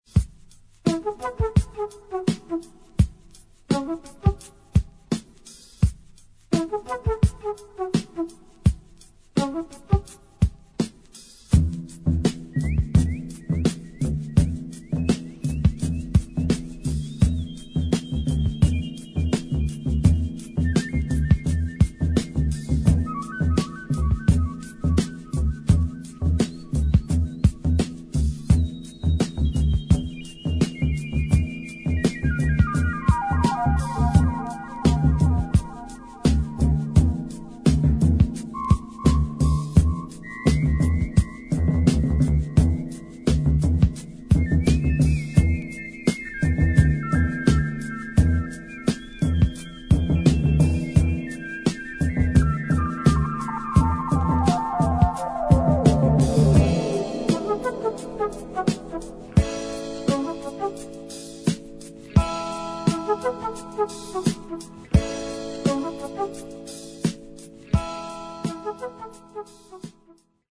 Ultra rare German soundtrack.
Killer beats, ready made hip-hop.